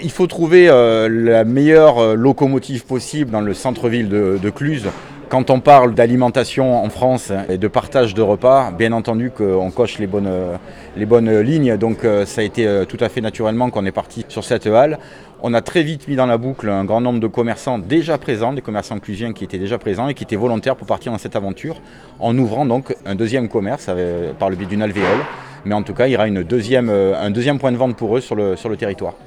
ITG Jean-Philippe Mas 3 – Halles gourmandes (29’’)